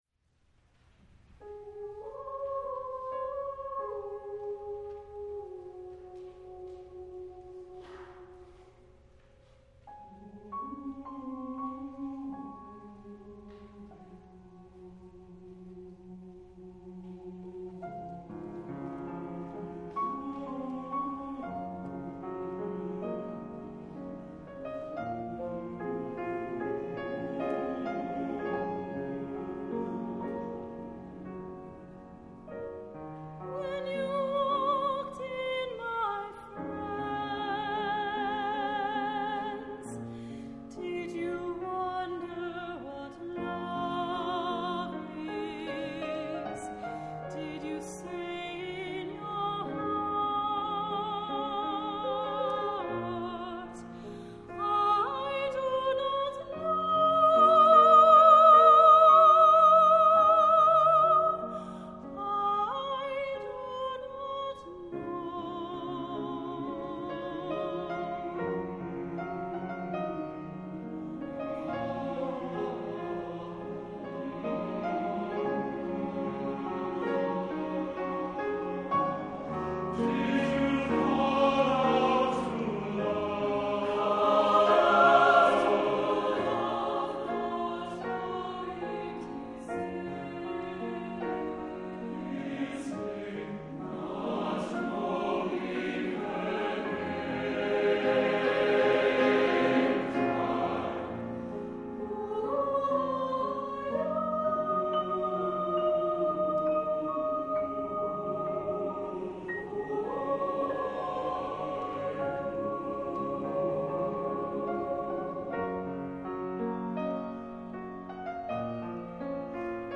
Voicing: SATB divisi and Piano